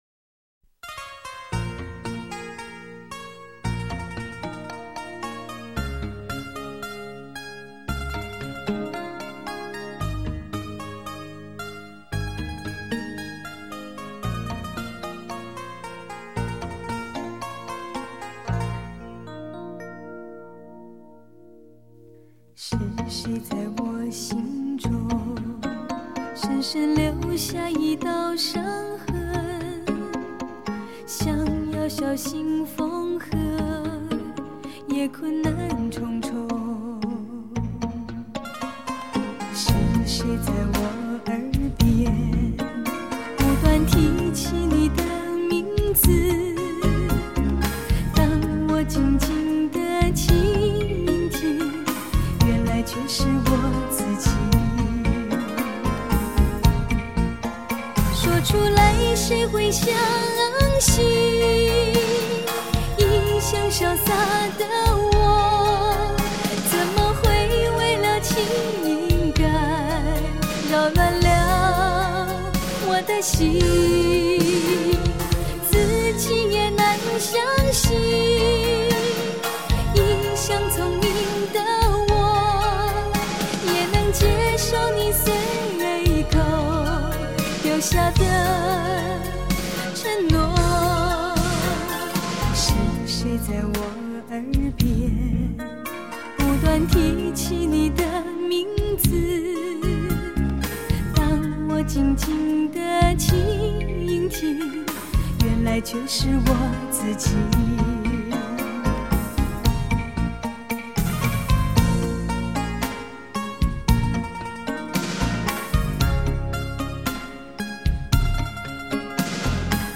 温柔婉约